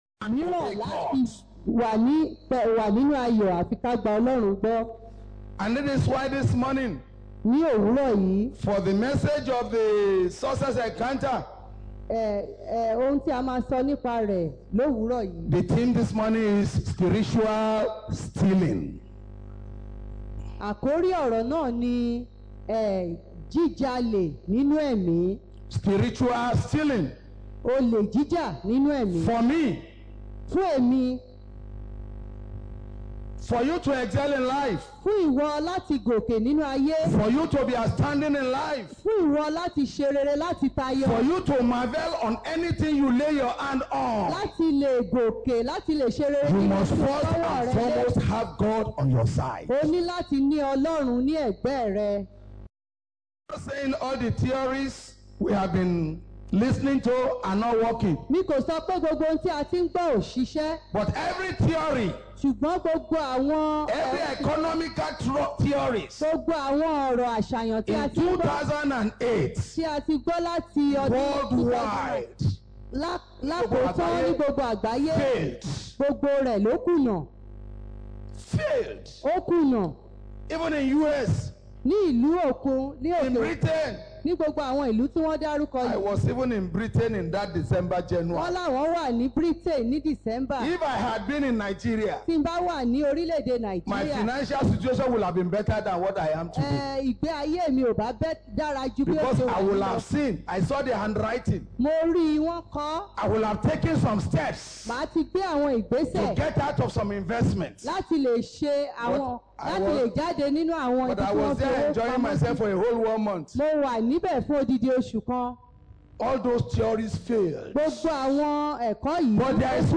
Spiritual Stealing – A success Encounter Sermon of 13th April 2014